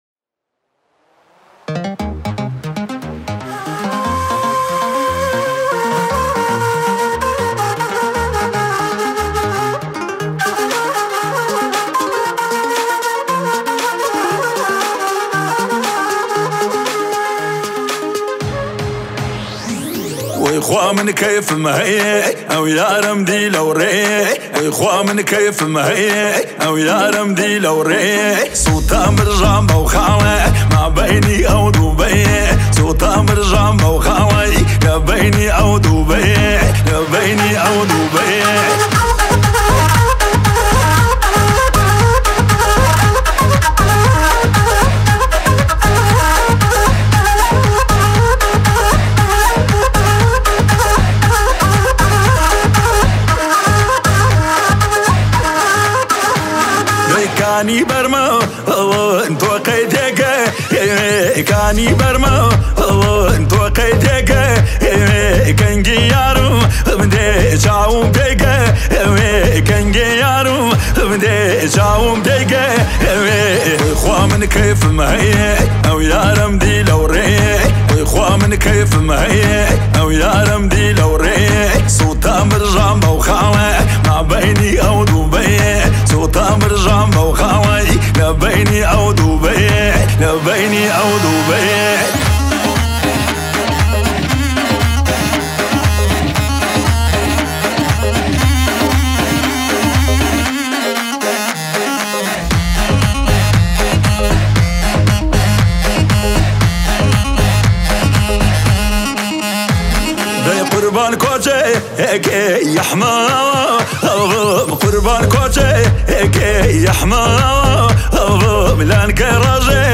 یک قطعه موسیقی شاد کردی